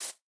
Minecraft Version Minecraft Version 25w18a Latest Release | Latest Snapshot 25w18a / assets / minecraft / sounds / block / bamboo / sapling_hit3.ogg Compare With Compare With Latest Release | Latest Snapshot
sapling_hit3.ogg